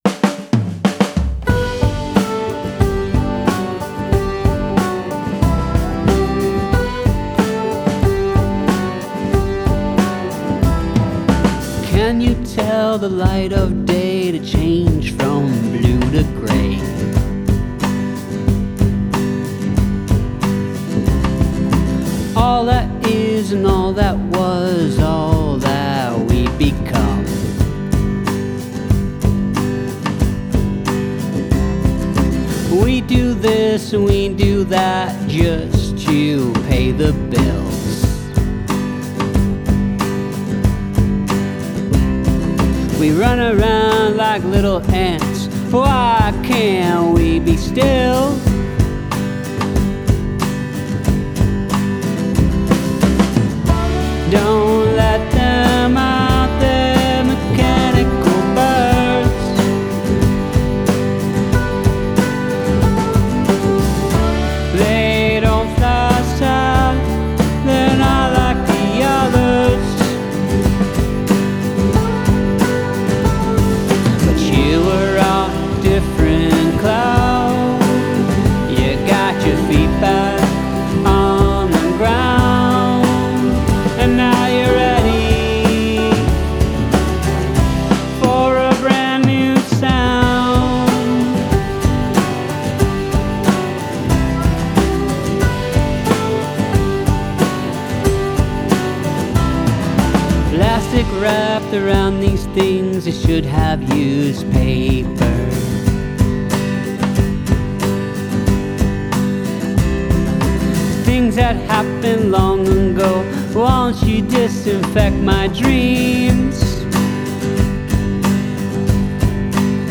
21st Century Folk Rock!